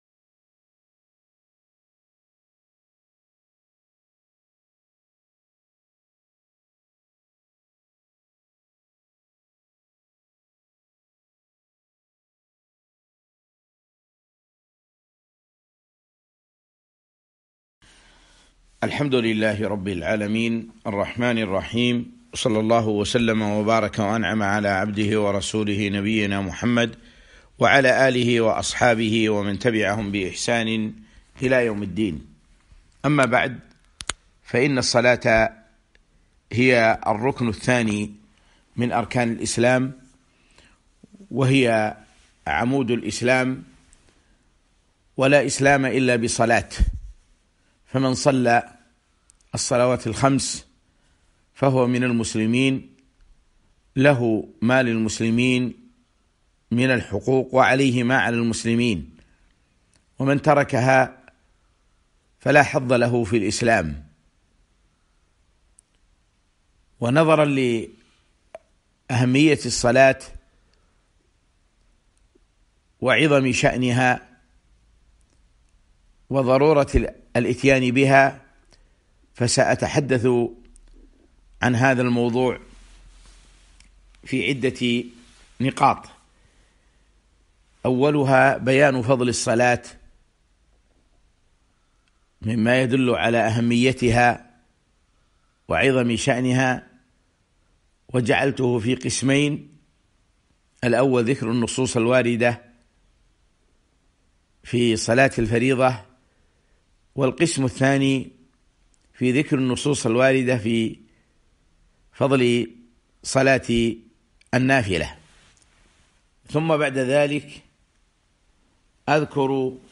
محاضرة - أهمية الصلاة في الإسلام